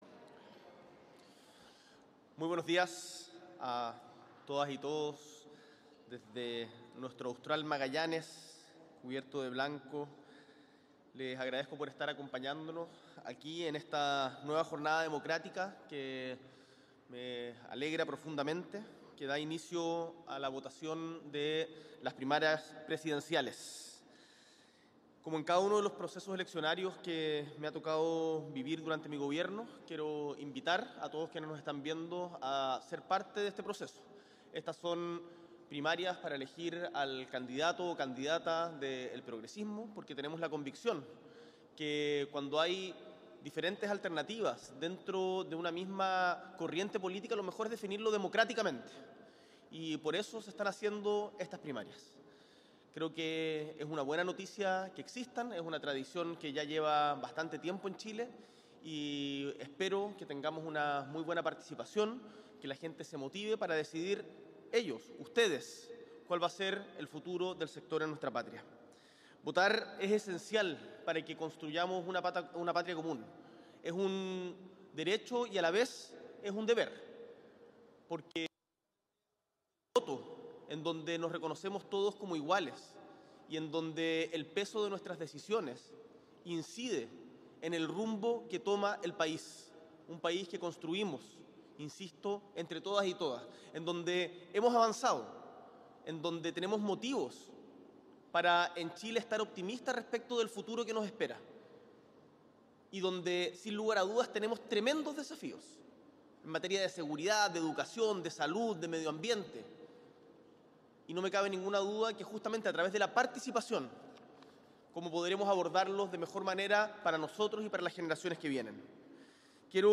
S.E. el Presidente de la República, Gabriel Boric Font, participa de las Primarias Presidenciales 2025